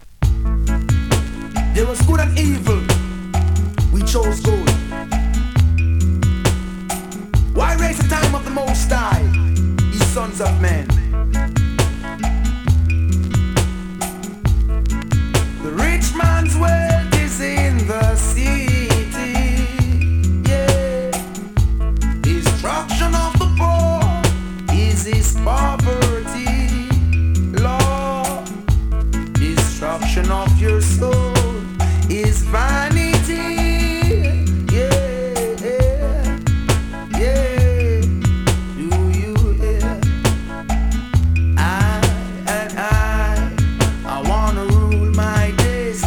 型番 SIDE 2:VERSION ※チリチリ有
スリキズ、ノイズかなり少なめの